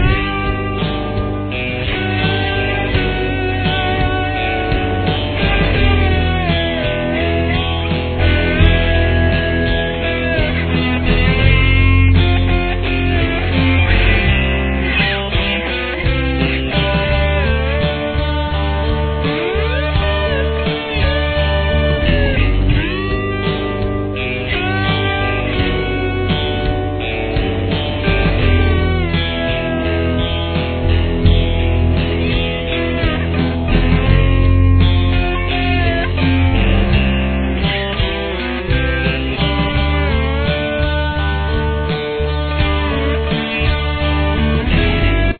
Slide Guitar